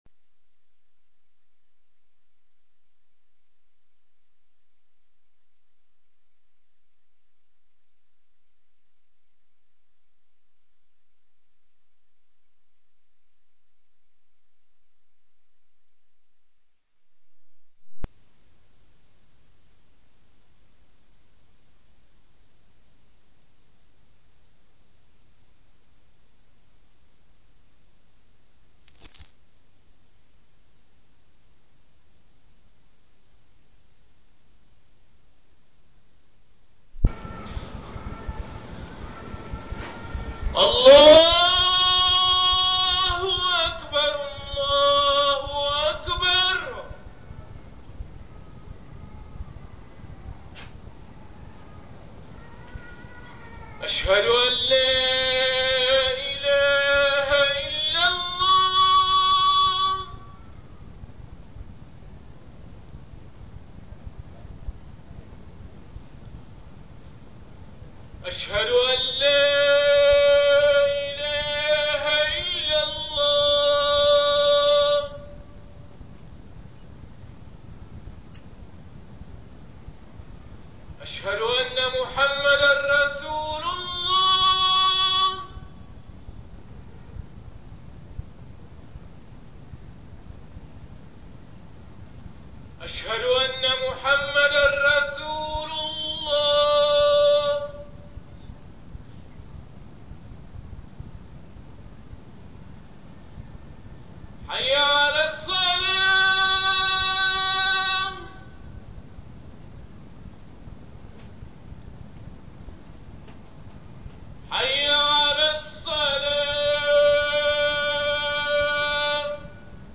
تاريخ النشر ١٧ جمادى الآخرة ١٤٠٨ هـ المكان: المسجد الحرام الشيخ: محمد بن عبد الله السبيل محمد بن عبد الله السبيل التواضع والكبر